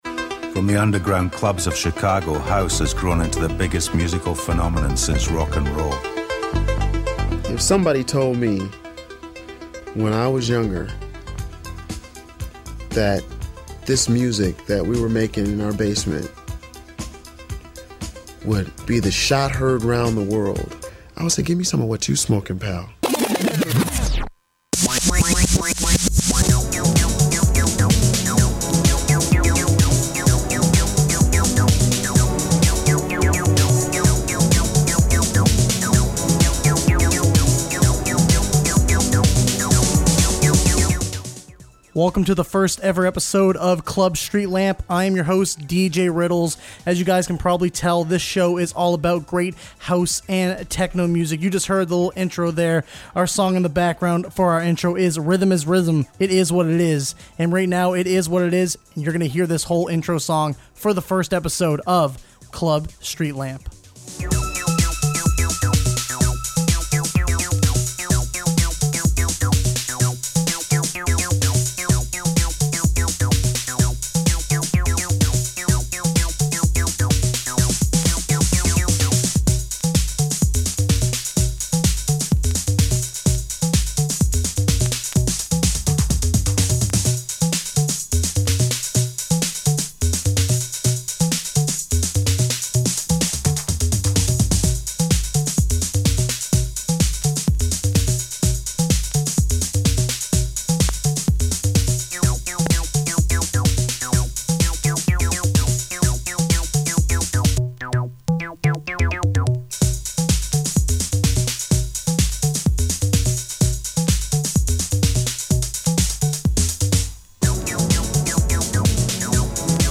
Late night House/Techno Music Program